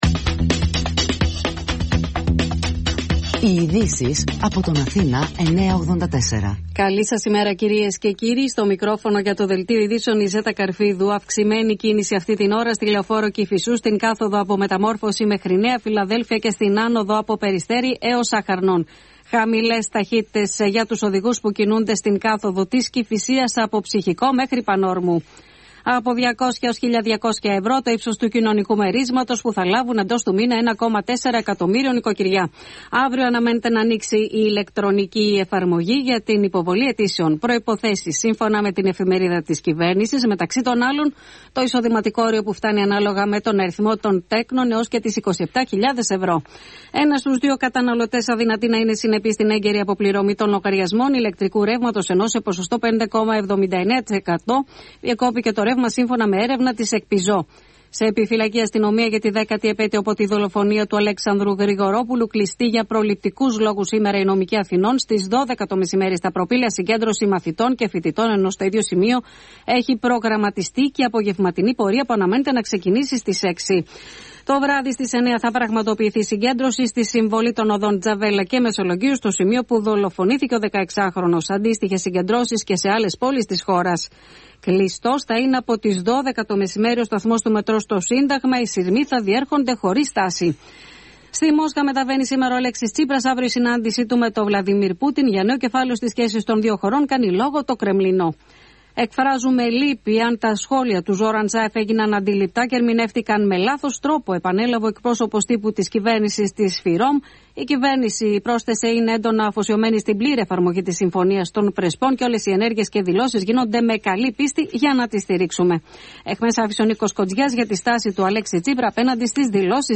Δελτιο Ειδησεων
Δελτίο ειδήσεων στις 10:00